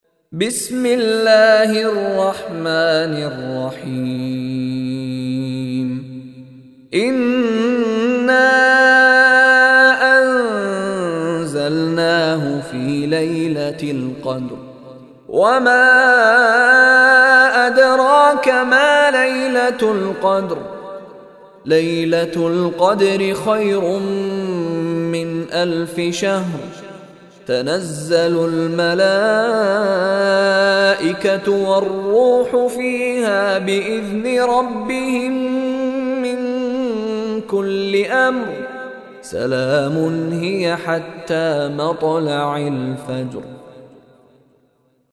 Surah Qadr MP3 Recitation by Mishary Rashid
Surah Al-Qadr is 97th chapter of Holy Quran. Listen online and download beautiful Quran tilawat / recitation of Surah Al-Qadr in the beautiful voice of Sheikh Mishary Rashid Alafasy.